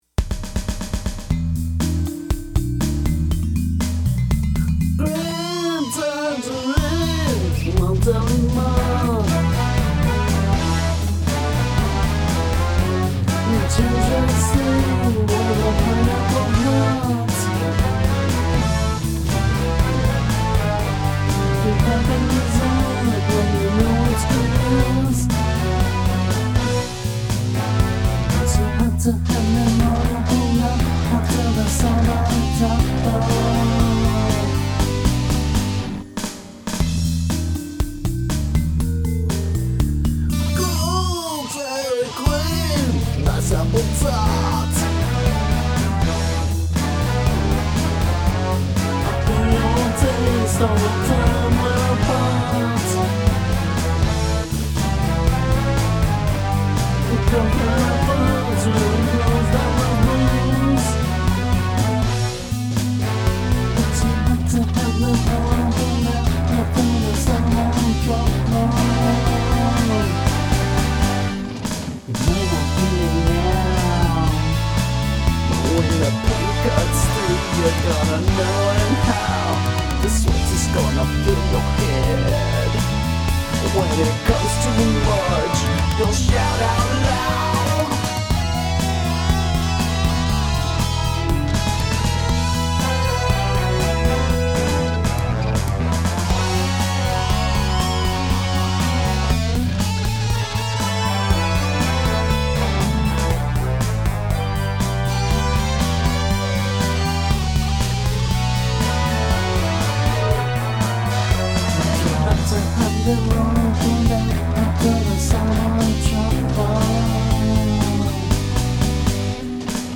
Vocals took about 9-10 takes.